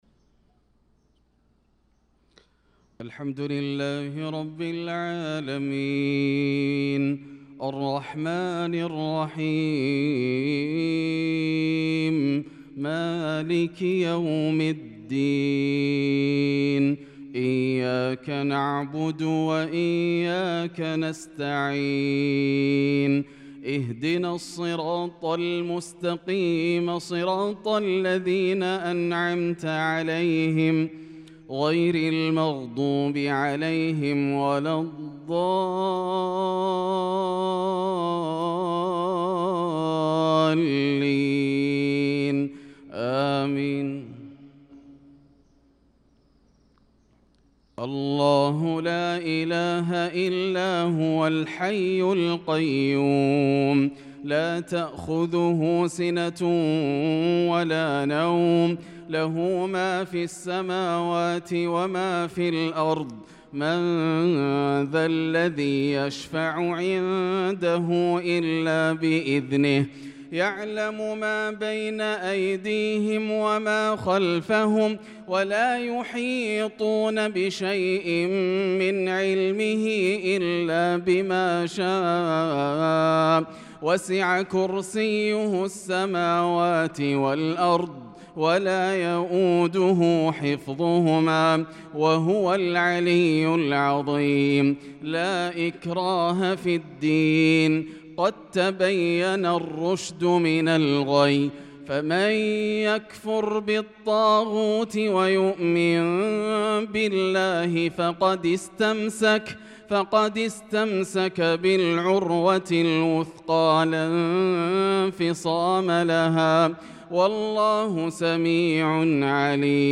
صلاة الفجر للقارئ ياسر الدوسري 21 شوال 1445 هـ
تِلَاوَات الْحَرَمَيْن .